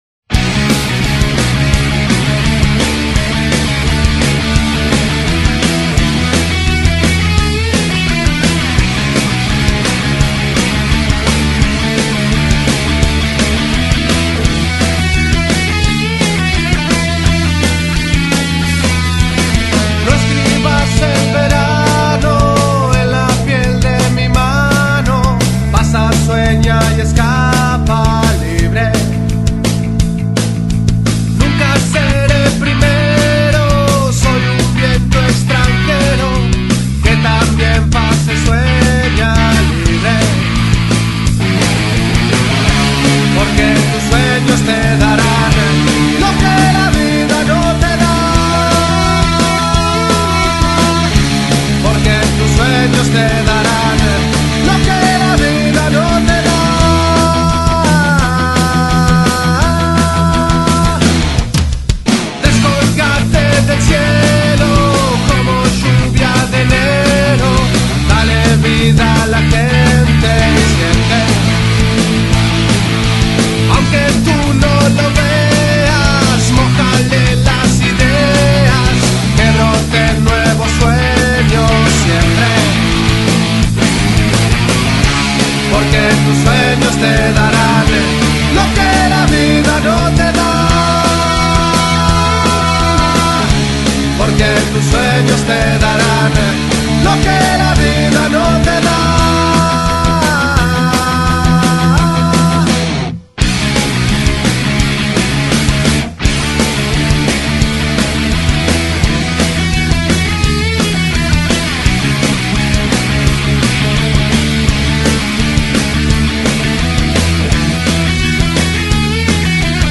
Carpeta: Rock uruguayo mp3